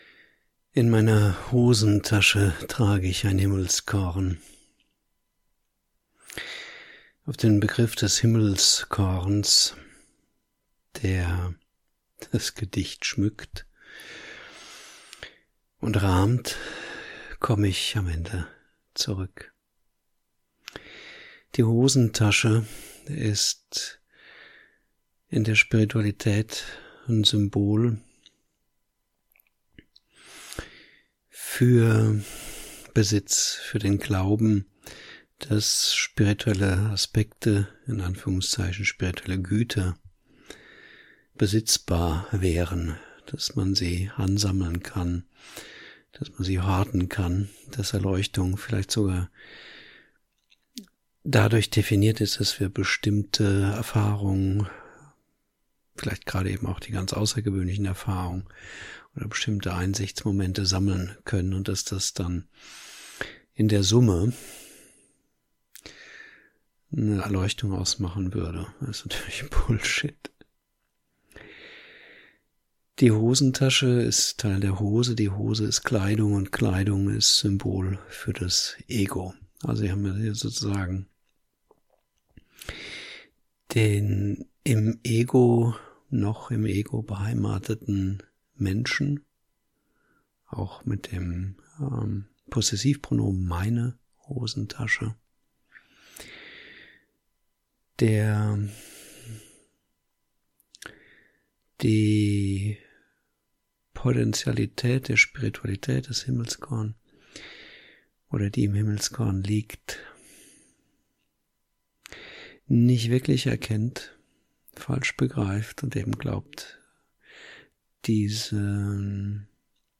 Auszug aus dem Vortrag: